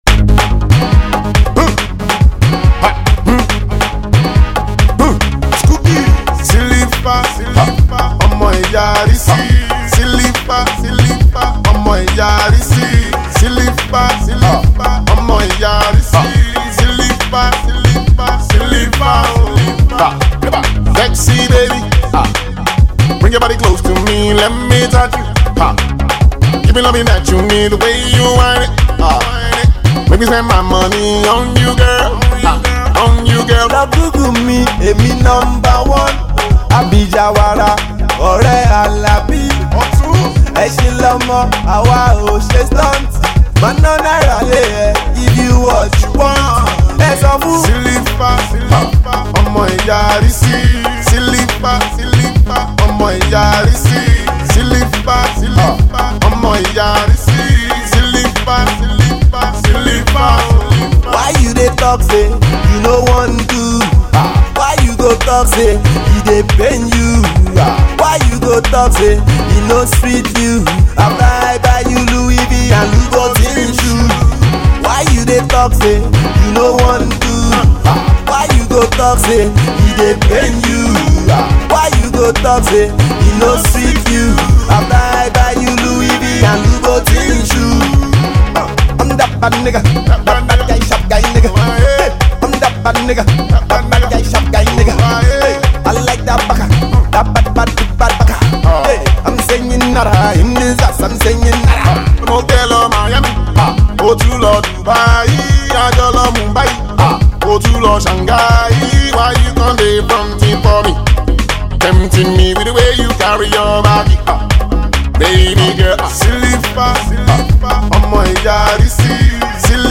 the Afro Pop duo
funky and catchy tunes